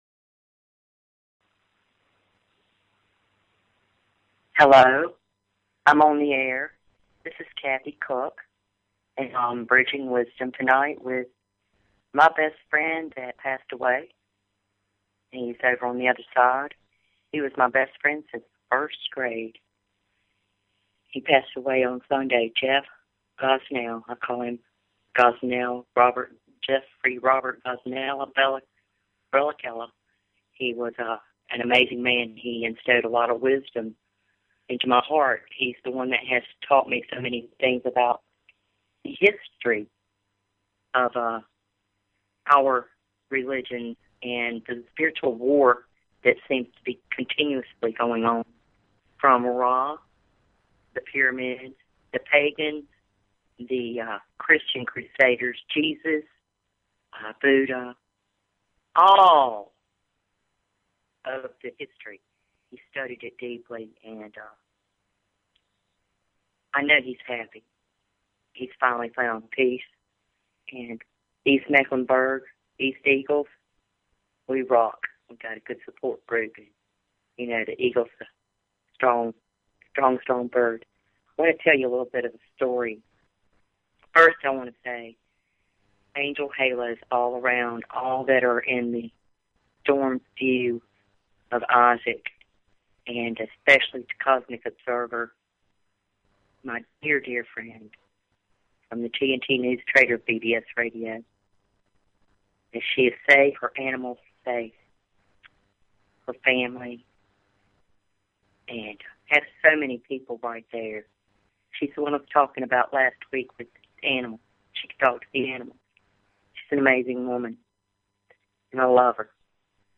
Talk Show Episode, Audio Podcast, Bridging_Wisdom and Courtesy of BBS Radio on , show guests , about , categorized as